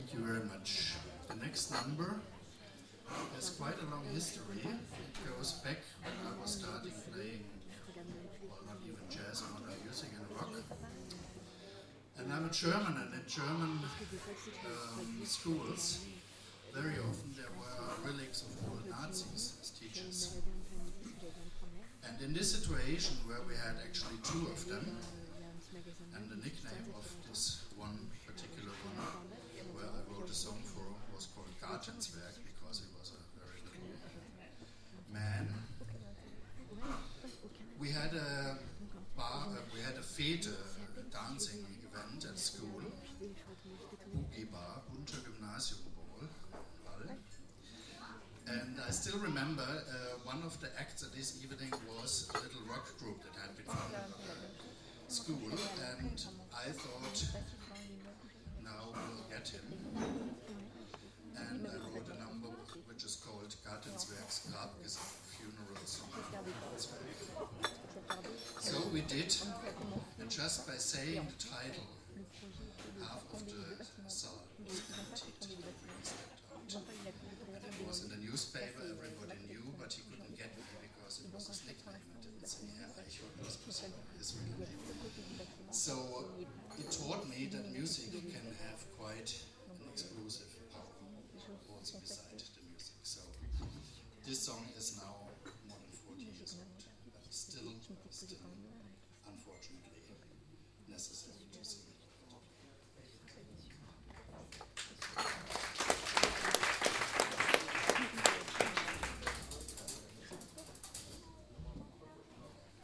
5 05 Ansage [1:53]